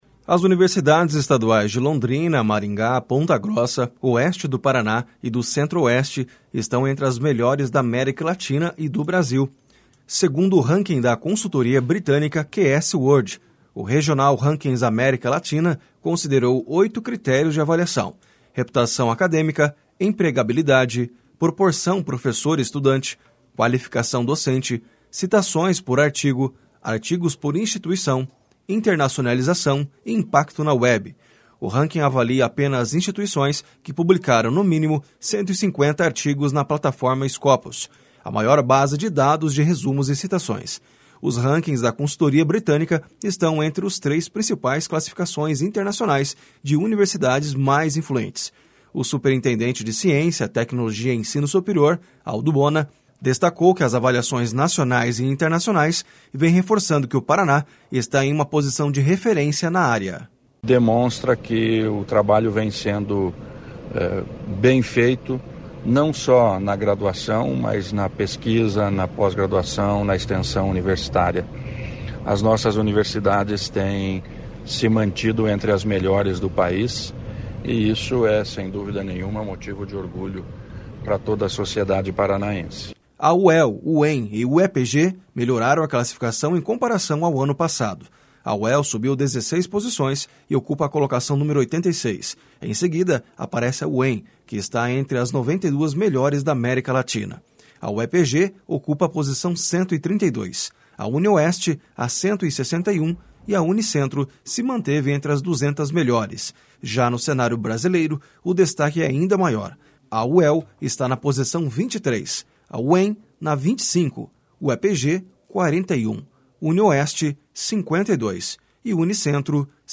// SONORA ALDO BONA //